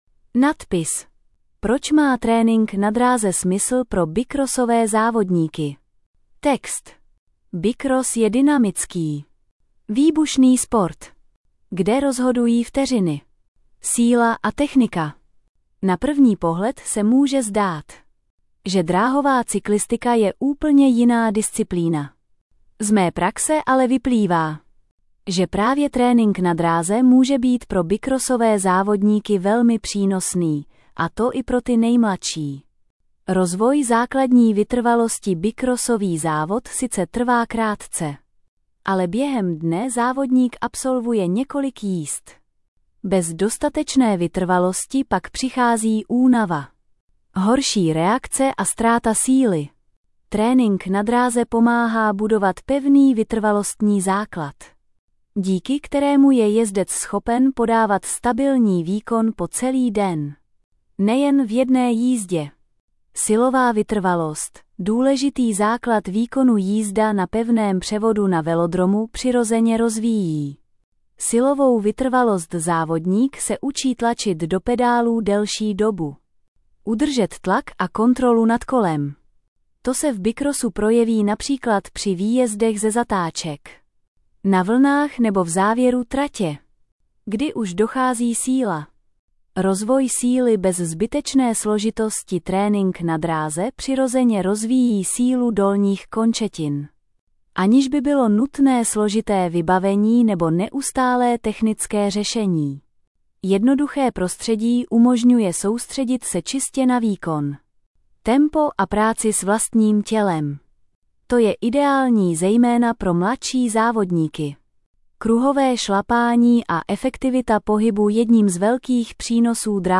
Vzdělávání Audio verze Poslech článku Otevřít audio Tvůj prohlížeč nepodporuje přehrávání audia.